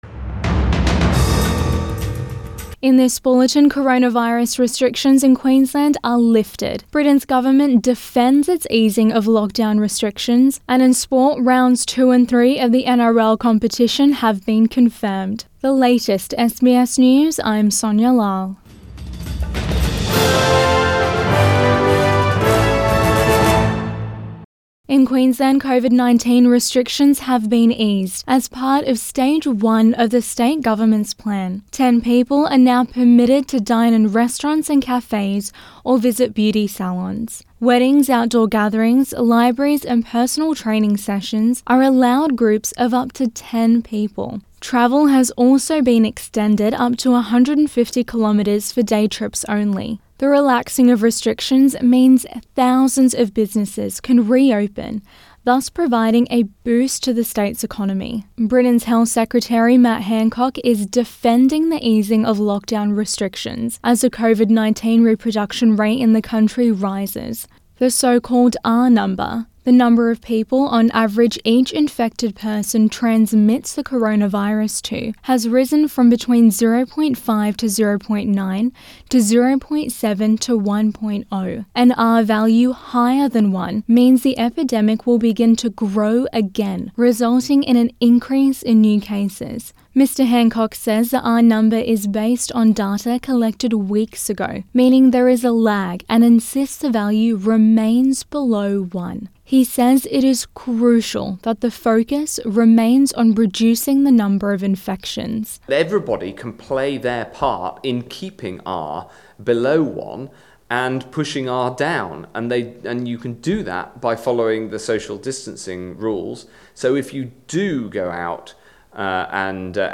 AM bulletin 16 May 2020